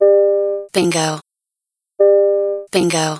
Ich habe schon vor Jahren vorgeschlagen, dass man Warnungen priorisieren kann, wird aber anscheinend zu wenig nachgefragt ;) Was ich dir noch empfehlen kann, wichtige Meldungen immer mit einem Signalton beginnen (siehe Beispiel).